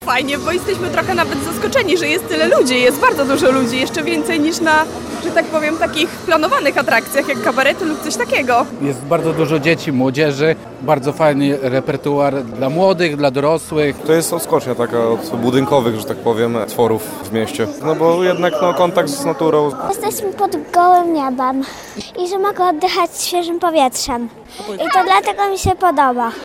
– To świetny sposób na spędzanie wakacji – mówią mieszkańcy Białej Podlaskiej.